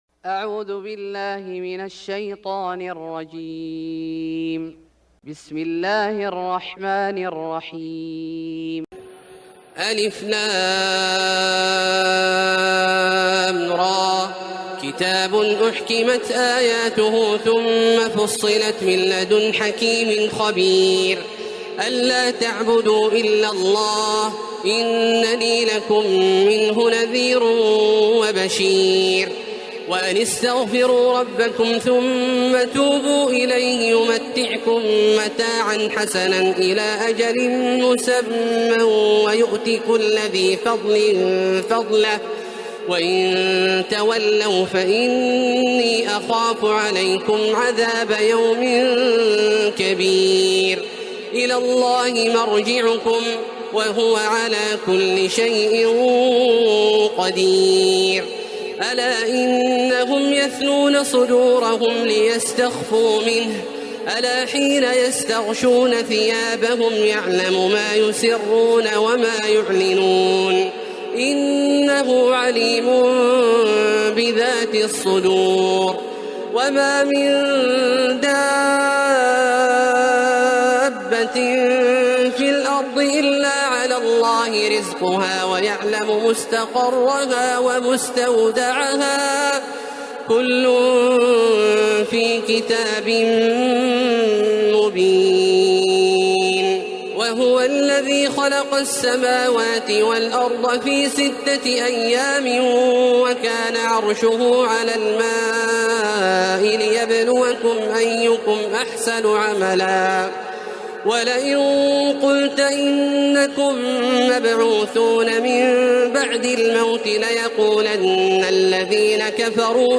سورة هود Surat Hud > مصحف الشيخ عبدالله الجهني من الحرم المكي > المصحف - تلاوات الحرمين